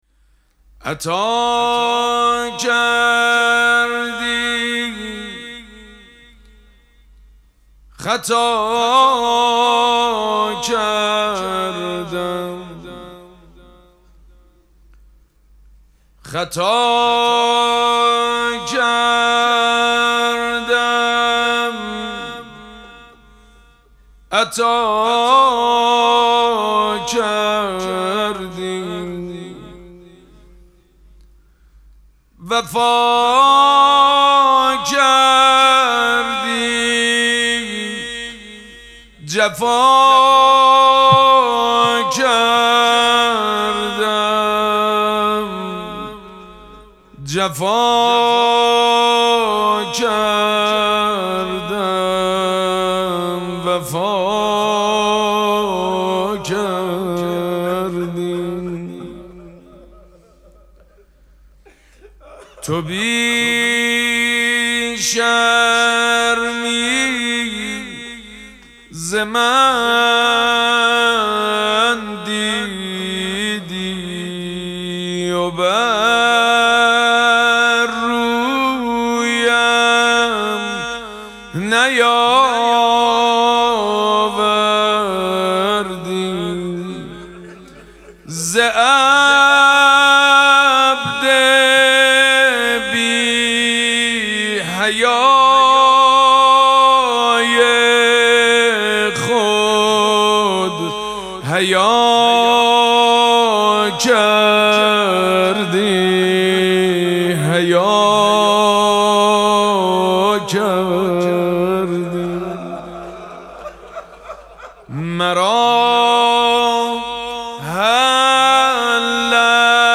مراسم مناجات شب دهم ماه مبارک رمضان
مناجات
مداح
حاج سید مجید بنی فاطمه